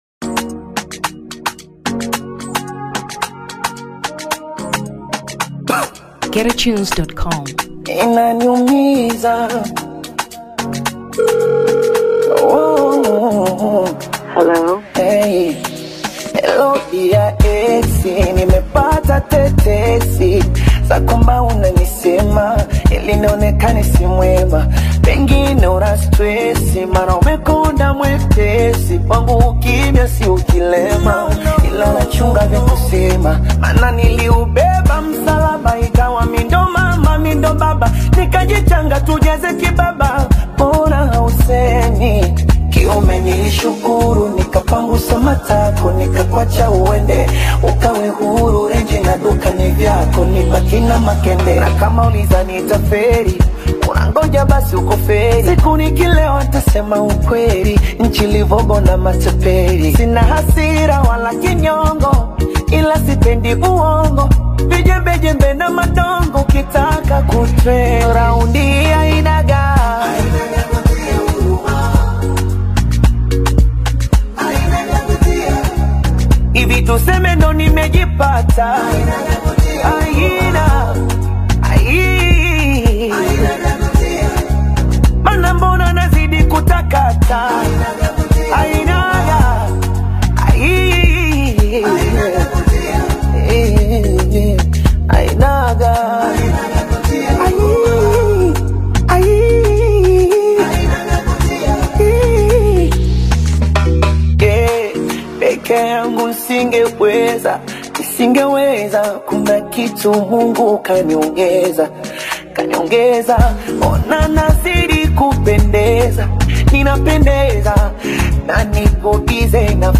Electronic 2023 Tanzania